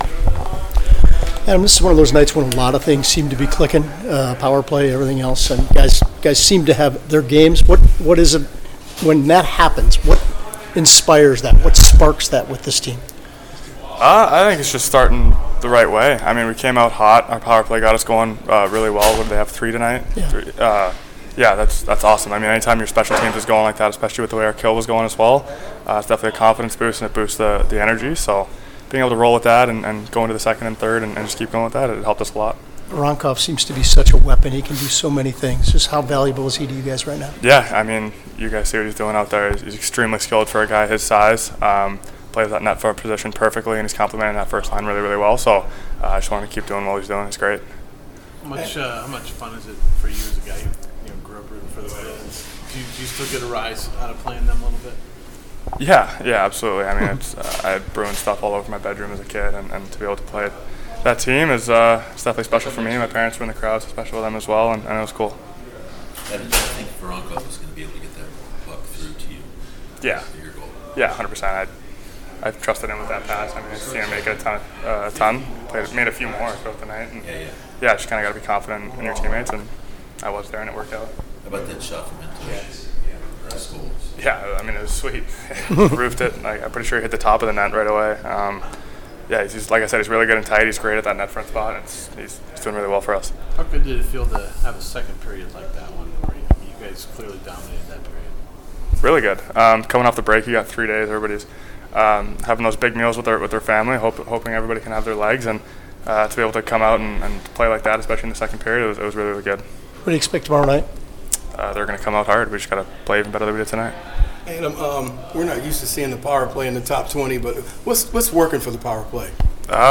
Adam Fantilli chats with media after team’s most recent win over Boston